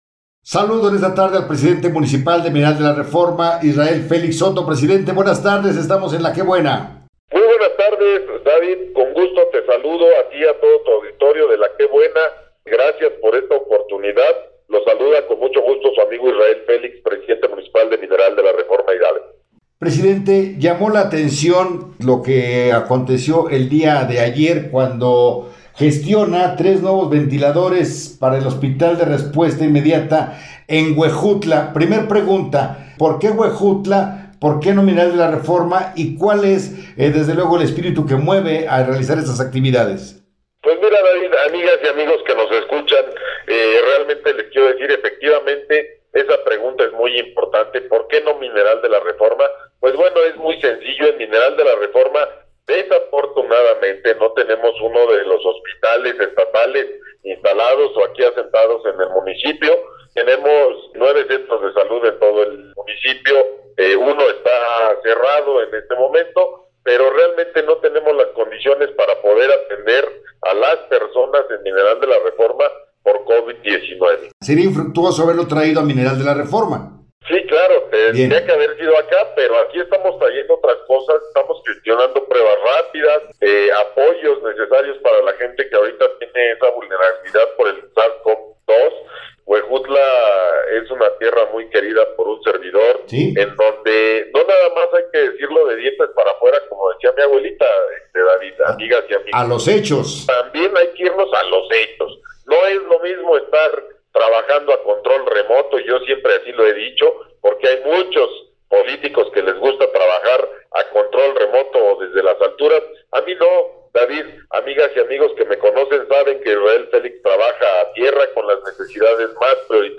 Entrevista
Pachuca, Hgo., 03 de febrero del 2021.- En entrevista, Israel Felix Soto, alcalde de Mineral de la Reforma, aseveró que la donación de 3 ventiladores que proporcionarán un soporte temporal o asistencia respiratoria a los pacientes que no pueden respirar por sus propios medios, se realizó al Hospital de Respuesta Inmediata de Huejutla dado que ahí se cuenta con las condiciones necesarias para su implementación.